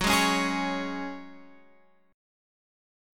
Gbsus2 chord